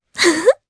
Laias-Vox_Happy1_jp.wav